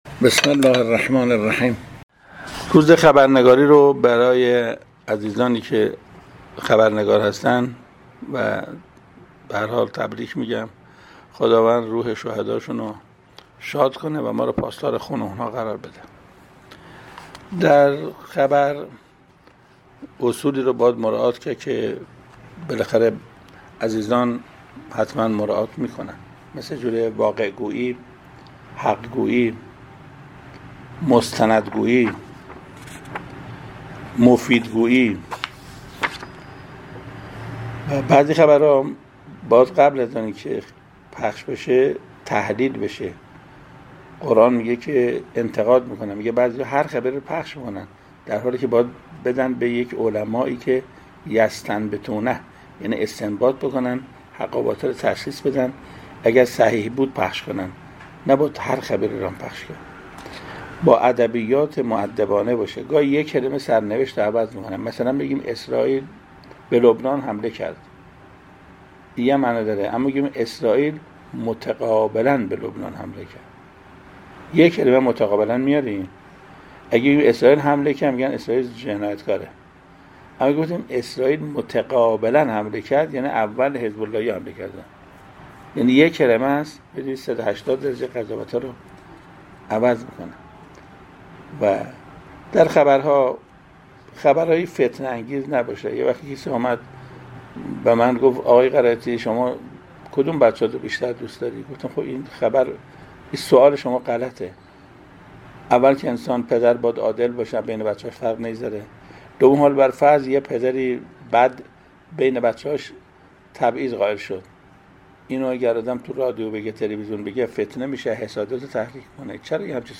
گروه چندرسانه‌ای ــ حجت‌الاسلام والمسلمین محسن قرائتی، معلم قرآن در پیامی روز خبرنگار را تبریک گفت و توصیه‌هایی را برای خبرنگاران تبیین کرد.